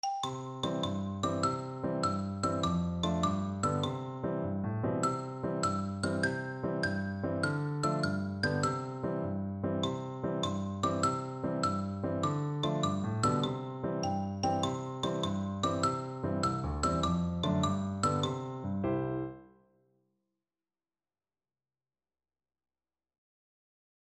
Xylophone
C major (Sounding Pitch) (View more C major Music for Percussion )
Quick two in a bar . = c.100
6/8 (View more 6/8 Music)
itsy_bitsy_XYL.mp3